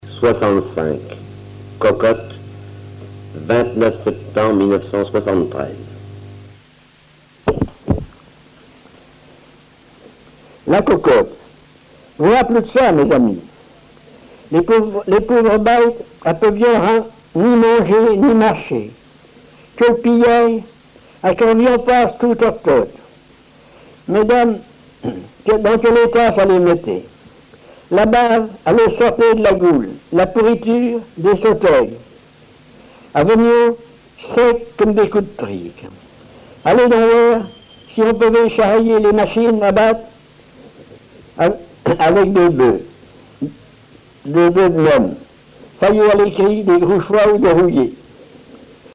Langue Patois local
Genre récit
Récits en patois